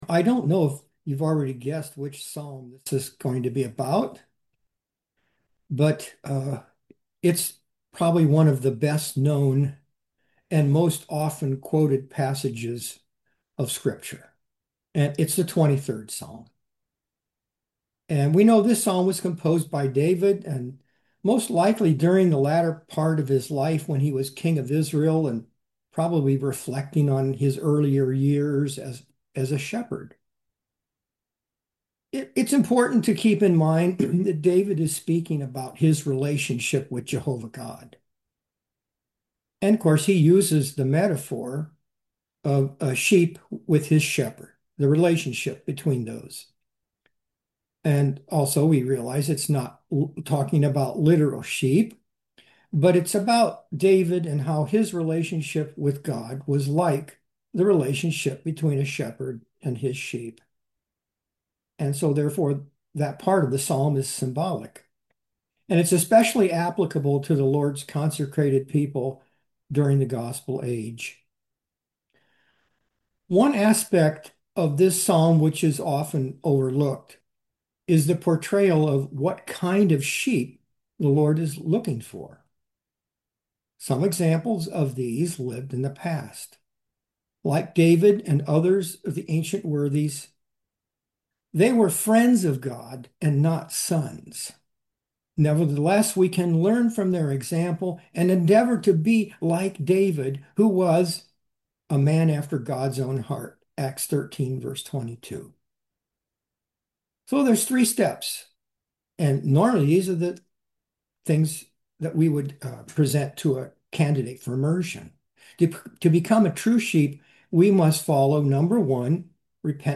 Series: 2026 Wilmington Convention